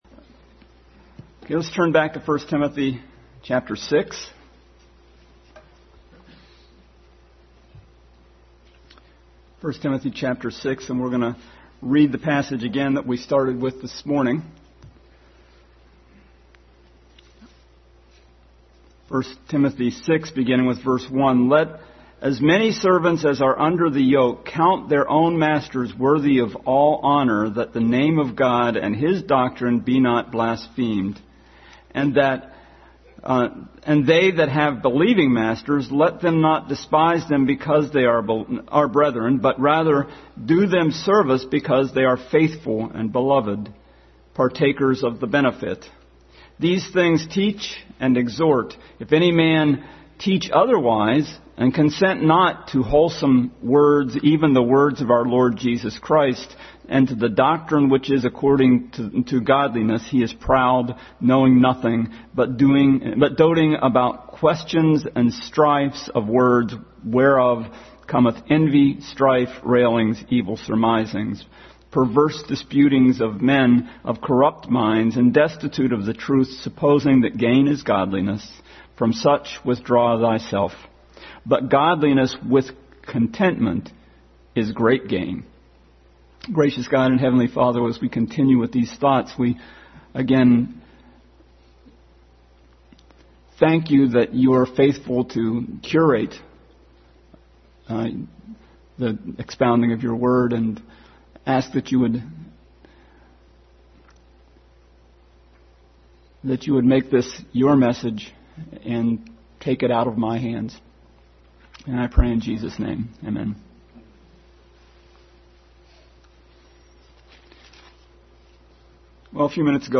Family Bible Hour Message.
6:1-9 Service Type: Family Bible Hour Family Bible Hour Message.